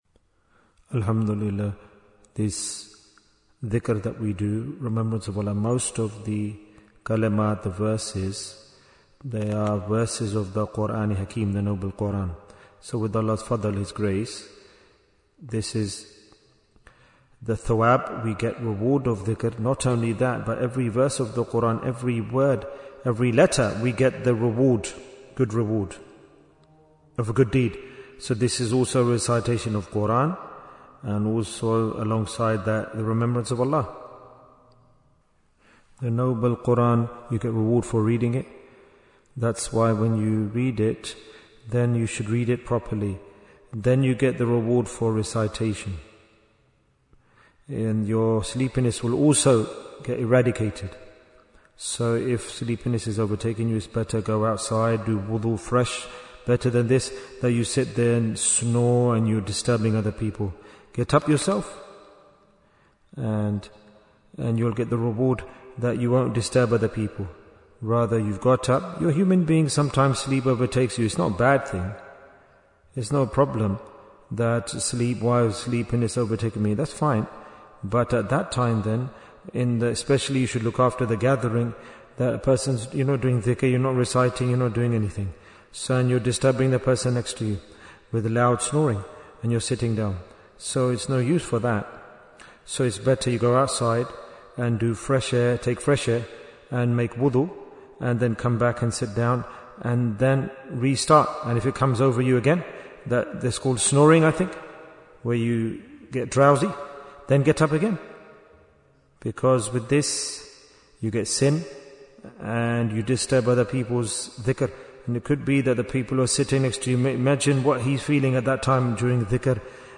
Jewels of Ramadhan 2026 - Episode 19 Bayan, 31 minutes28th February, 2026